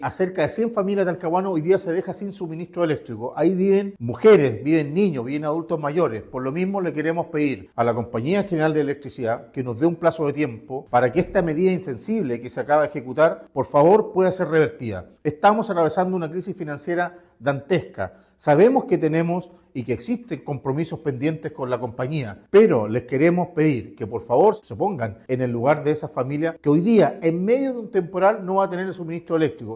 alcalde-talcahuano.mp3